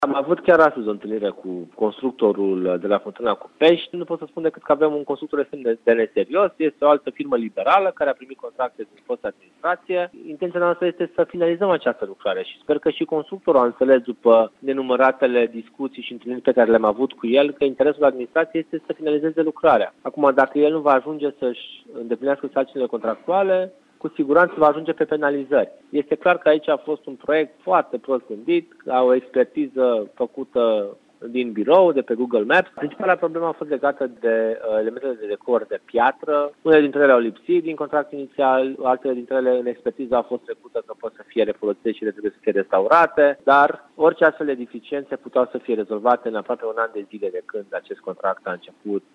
Viceprimarul Timișoarei, Ruben Lațcău, spune că vina o poartă constructorul despre care consideră că este neserios.